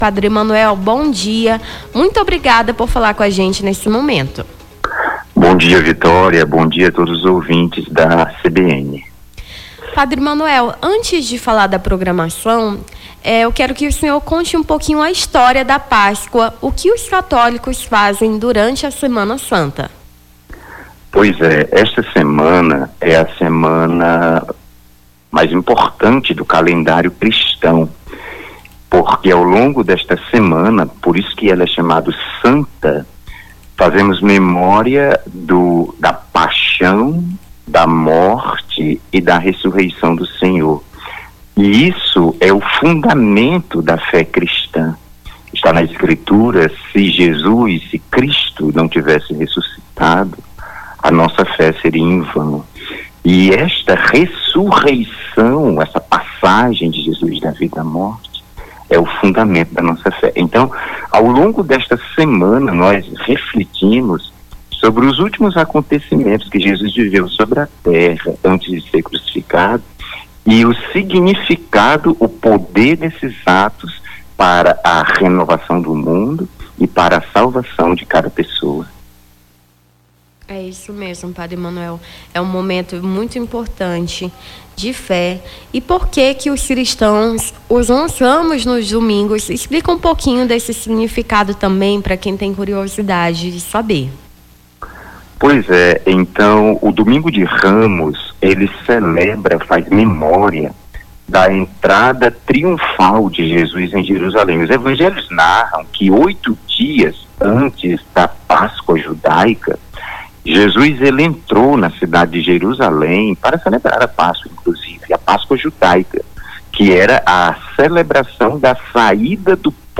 Nome do Artista - CENSURA - ENTREVISTA (PROGRAMAÇÃO SEMANA SANTA) 17-04-25.mp3